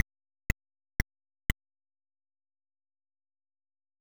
MenuClick.wav